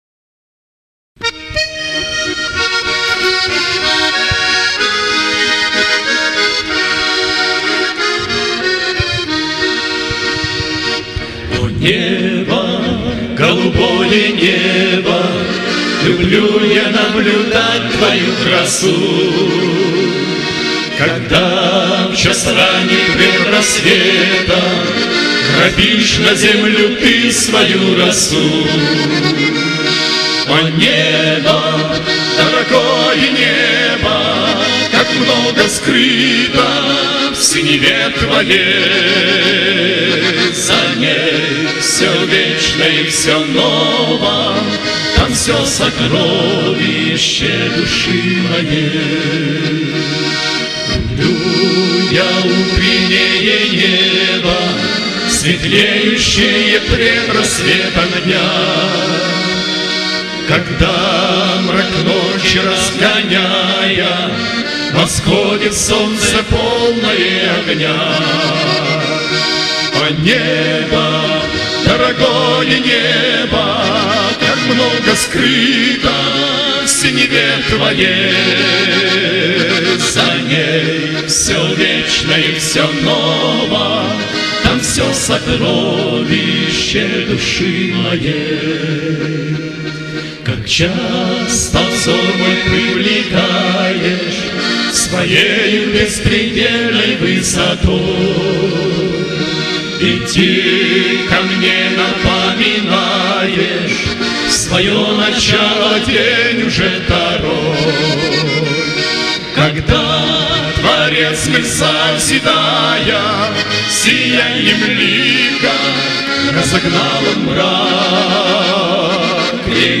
Евангелизационное служение с участием музыкальной группы